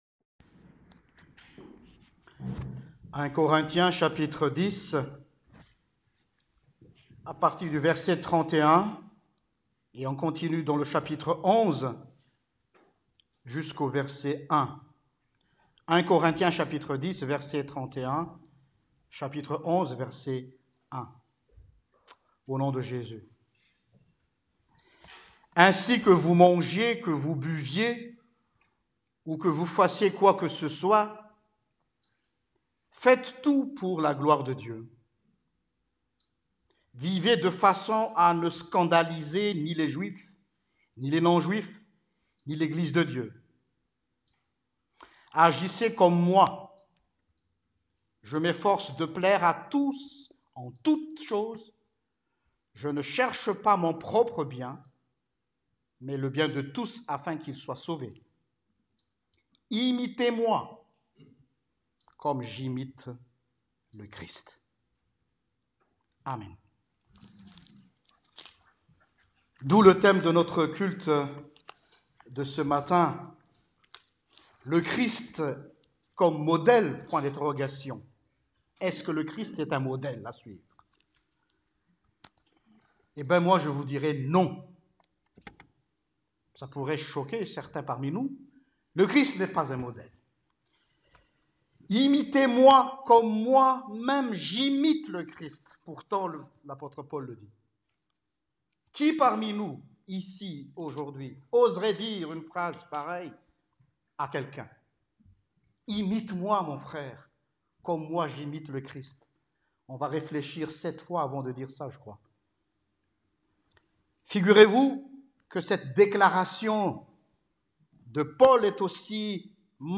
Prédication du 11 Février 2018: LE CHRIST, MODÈLE?…..NON!